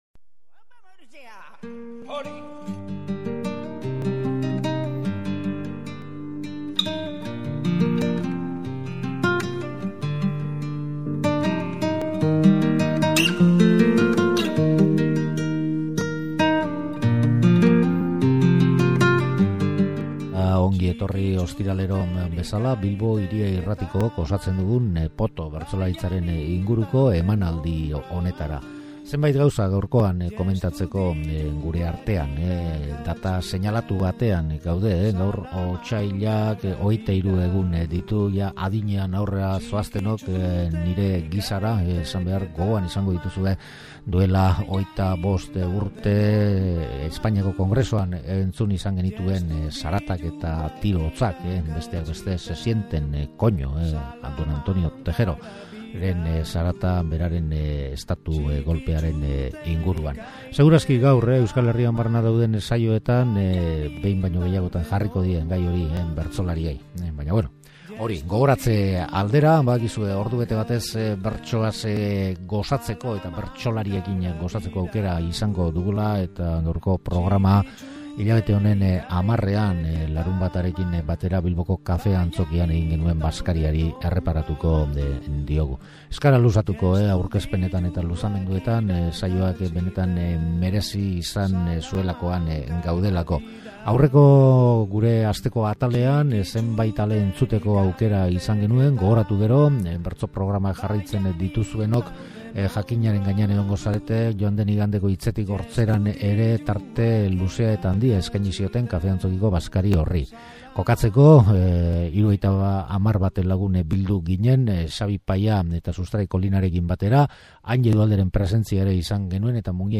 Nork esan du bertsolariek ez dakitela couplé doinuan eta espainolez kantatzen?